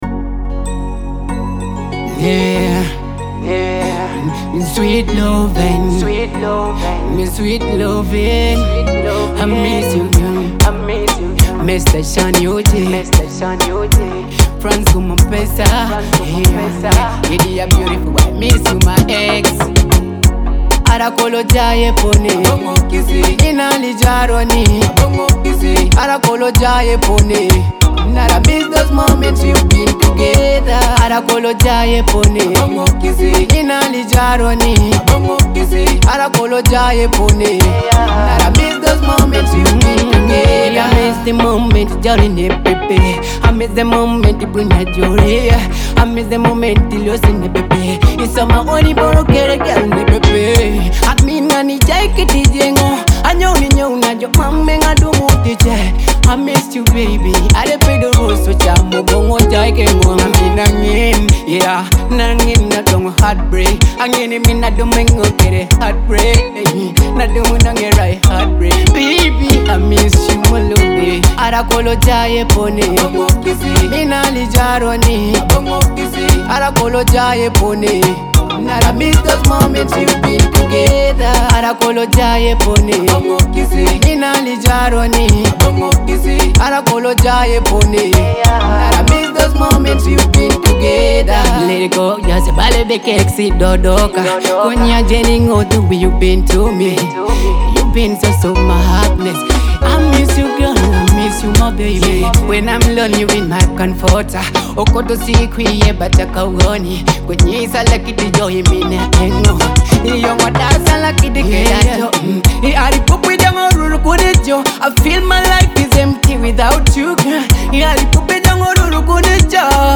Teso music